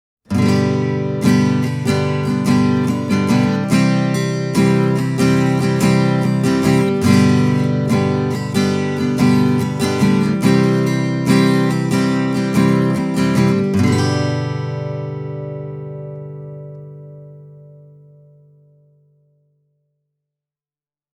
Fender’s Paramount PM-2 AM is a parlour-sized steel-string acoustic (similar in size to C.F. Martin’s size 00) with a 12th fret neck joint.
Don’t get me wrong, though: The Fender PM-2 All Mahogany doesn’t sound thin, or puny, or sharp – its voice is beautiful, well-balanced and warm. It’s just that the tidier bass response will keep the bass register from swamping everything else, unlike when using certain Dreadnought models.
The PM-2 AM is a fantastic player, and it offers you the warm, but open tones, you’d associated with a quality exponent of the 00-size guitar.